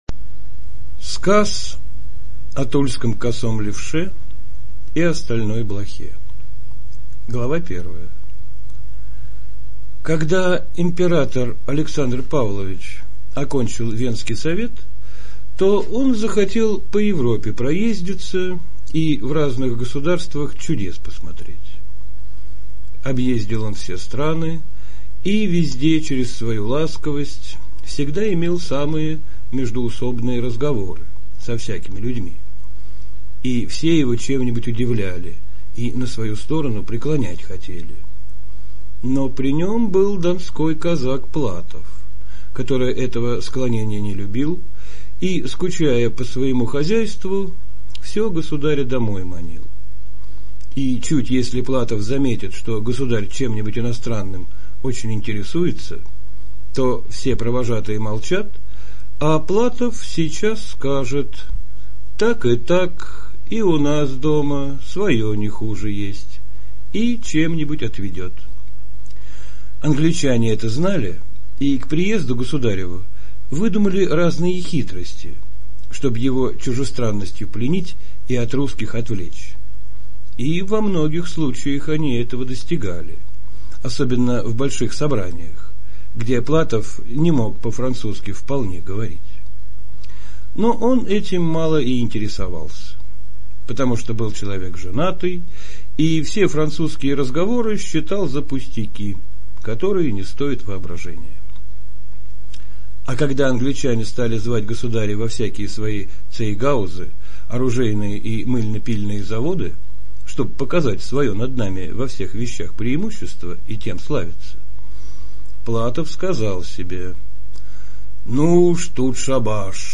Левша - аудио повесть Лескова - слушать онлайн